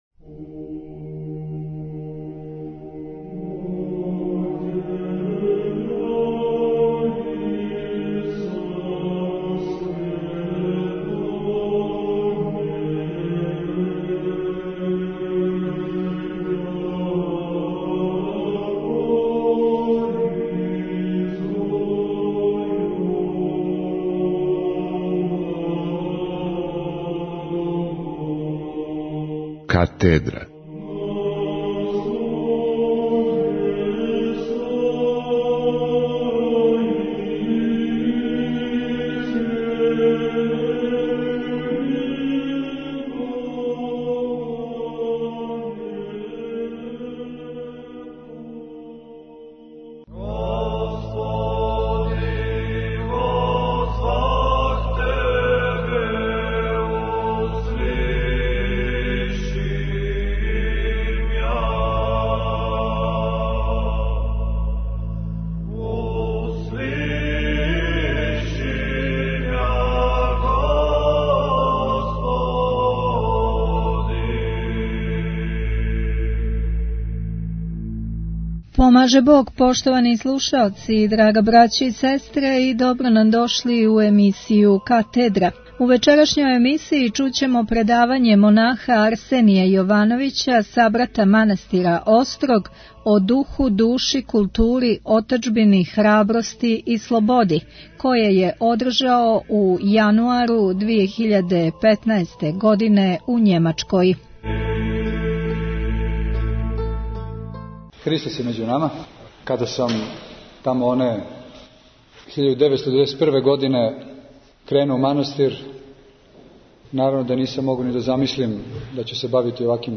Митрополит Амфилохије одржао предавање у Барселони